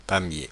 Pamiers (French pronunciation: [pamje]
Fr-Pamiers.oga.mp3